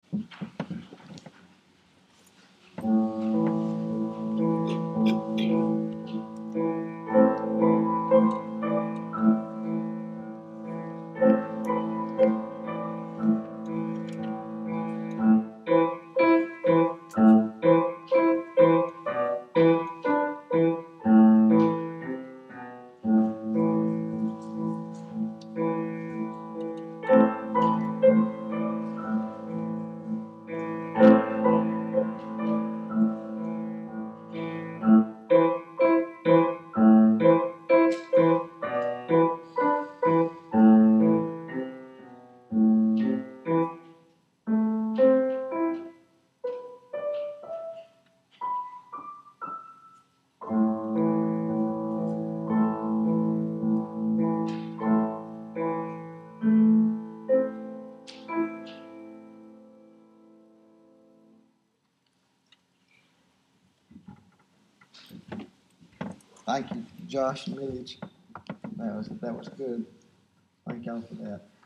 Offertory
music (pastor & son)